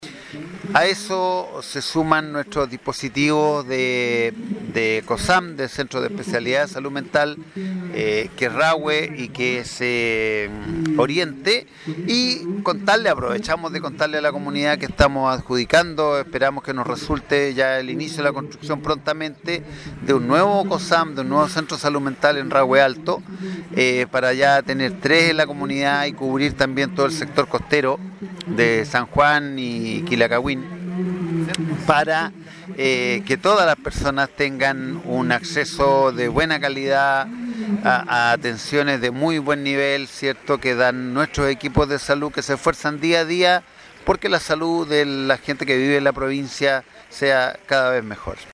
Junto con destacar el trabajo de los distintos dispositivos de salud mental, así como la participación en la feria de las diferentes organizaciones, de la comunidad y la presentación de la banda municipal, el director del Servicio de Salud, Rodrigo Alarcón, subrayó que “con el tiempo y con el desarrollo hemos ido aprendiendo que los elementos fundamentales para proteger la salud mental son la vida en comunidad y los buenos hábitos de salud, que nos permiten protegernos, tener vidas más largas y mejores”.